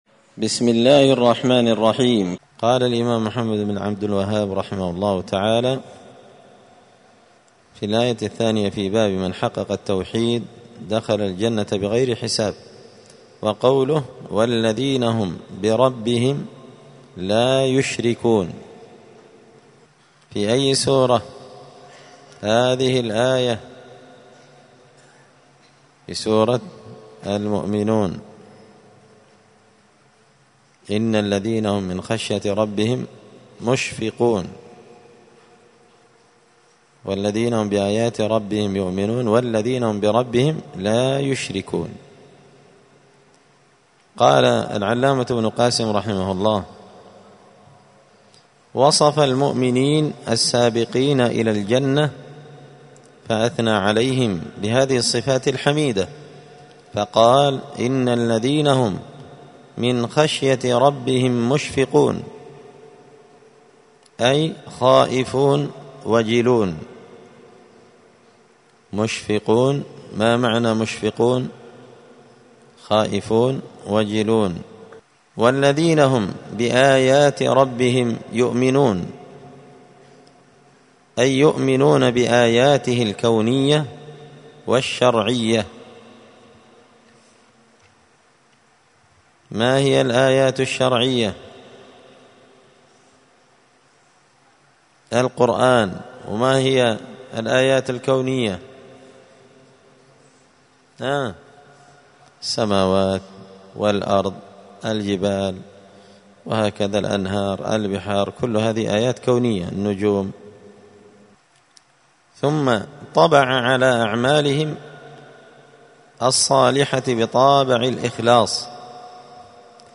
دار الحديث السلفية بمسجد الفرقان بقشن المهرة اليمن
*الدرس الثالث عشر (13) {تابع للباب الثالث باب من حقق التوحيد دخل الجنة بغير حساب…}*